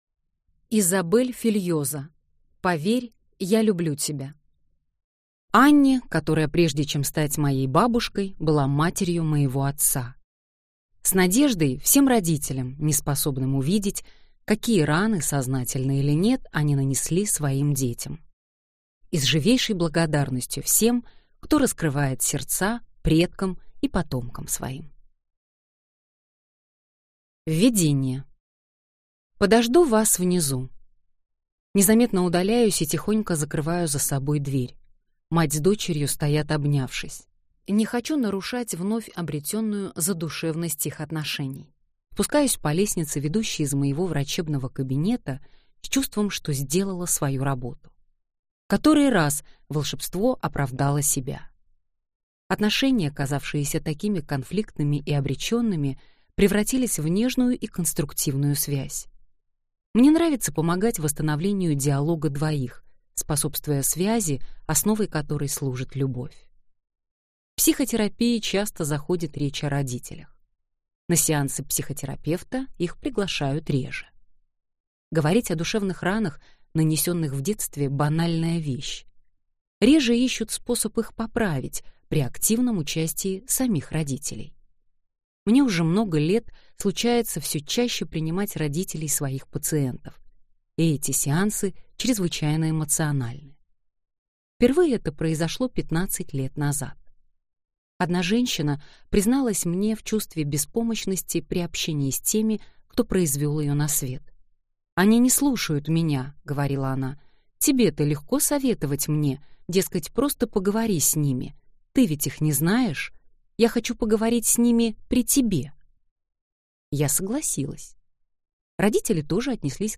Аудиокнига Поверь. Я люблю тебя | Библиотека аудиокниг